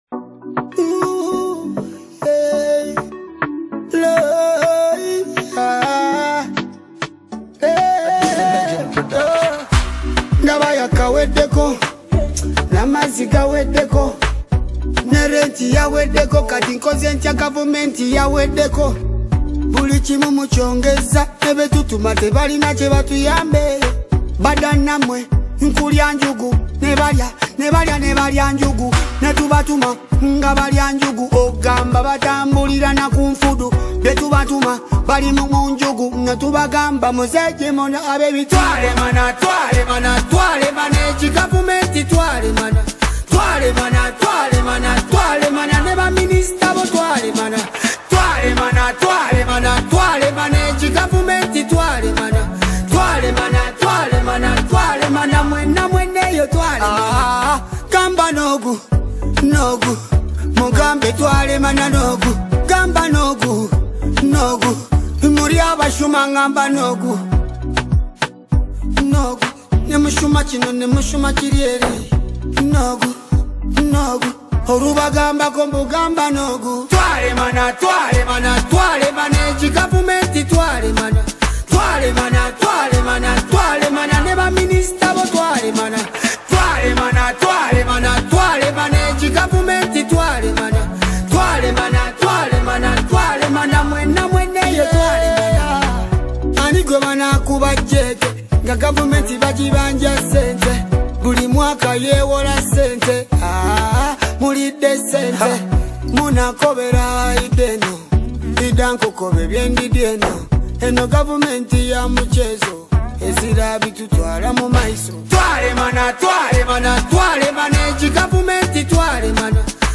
Afro Beat